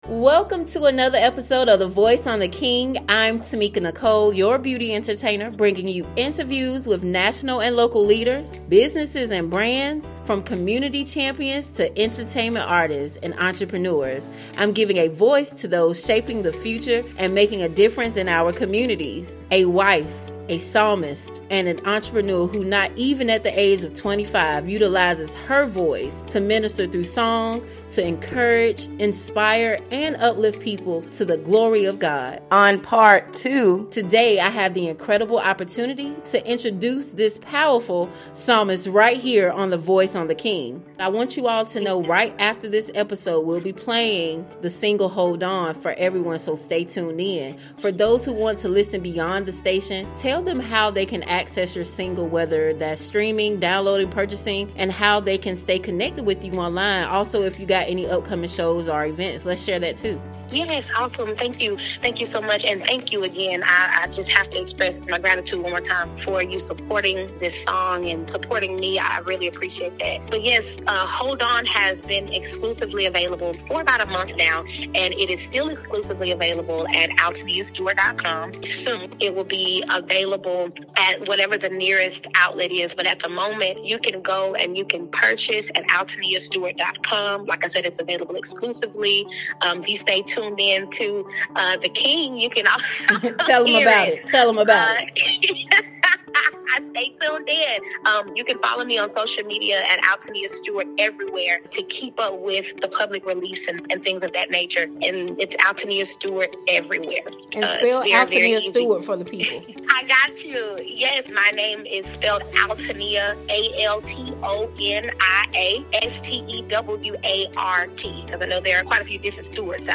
is bringing you exclusive, fast-paced interviews with national and local leaders